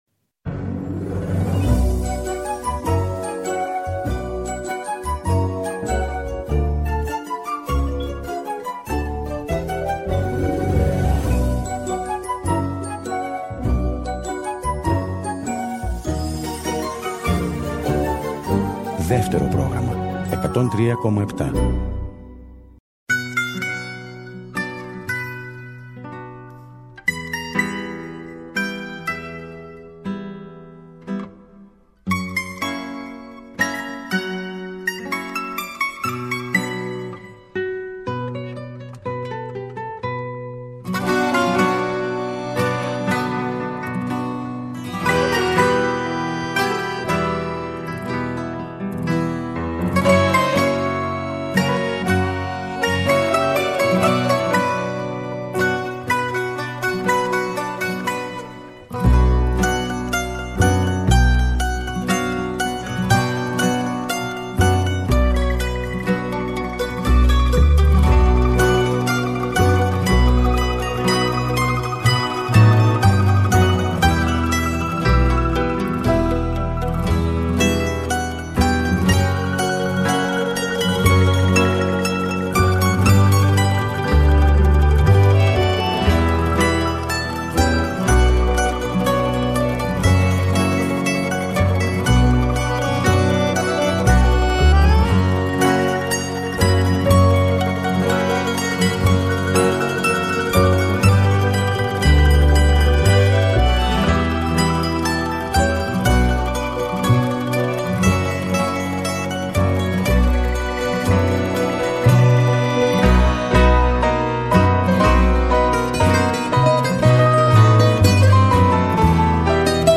παρουσιάζει τραγούδια και μουσικές του συνθέτη, αλλά και προσωπικές αναμνήσεις από τη συνεργασία τους.